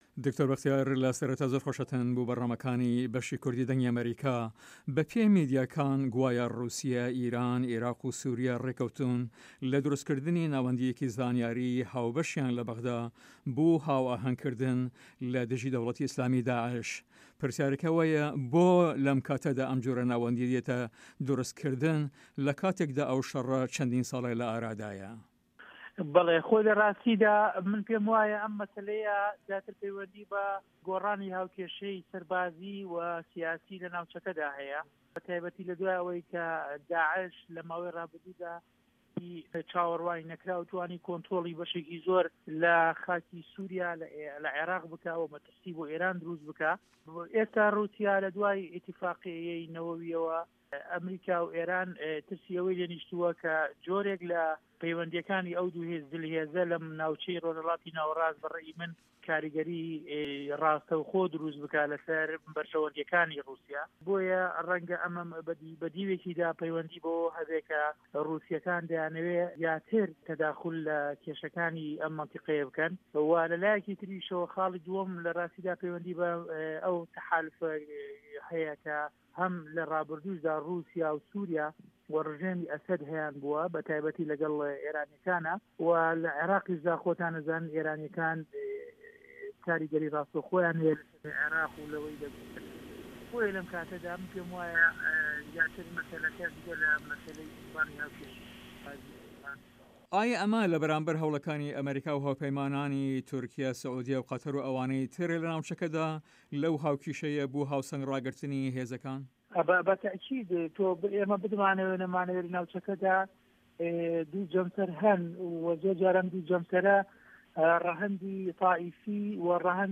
دکتۆر بەختیار شاویس ئەندام پەرلەمانی عێراق لەسەر لیستی یەکێتی نیشتمانی کوردستان لە هەڤپەڤینێکدا لەگەڵ بەشی کوردی دەنگی ئەمەریکا دەڵێت" من لەو بروایەدام ئەم مەسەلەیە زۆرتر پەیوەندی بە گۆڕانی هاوکێشەی سەربازی و سیاسی لە ناوچەکەدا هەیە بە تاێبەتی لە دووای ئەوەی داعش لە ماوەی رابوردوودا بە شێوەیەکی چاوەروان نەکراوو توانی کۆنترۆڵی بەشێکی زۆر لە خاکی سوریا و عێراق بکات و مەترسی بۆ عێراق دروست بکات.